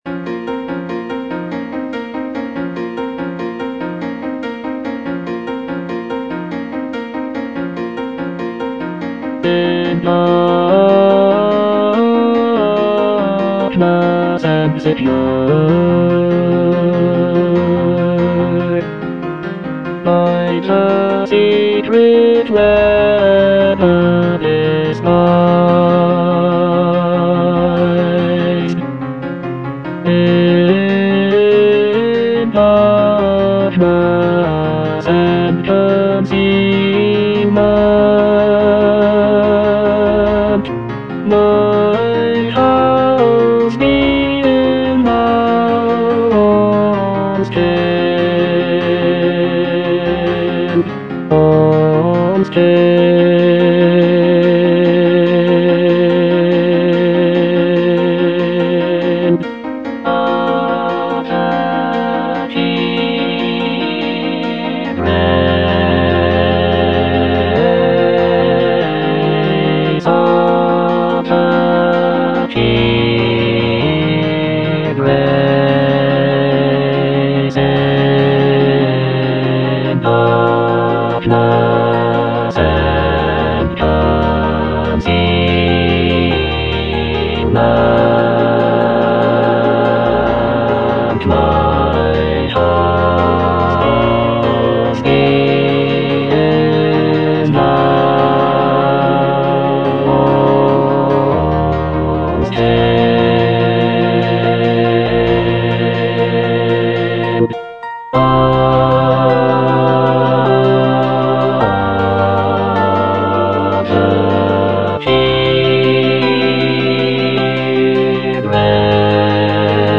bass II) (Emphasised voice and other voices) Ads stop